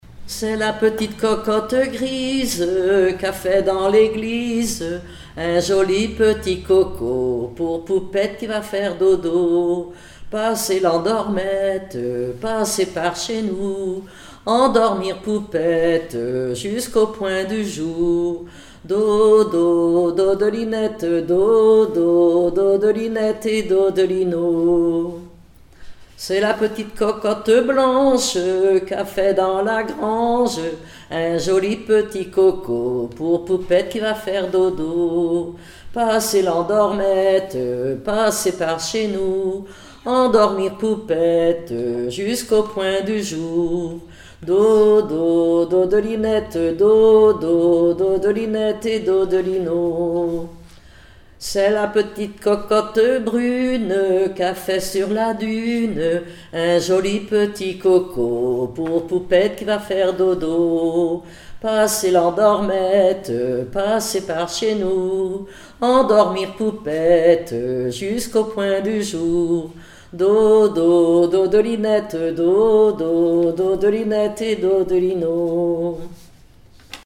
Mémoires et Patrimoines vivants - RaddO est une base de données d'archives iconographiques et sonores.
enfantine : berceuse
Pièce musicale inédite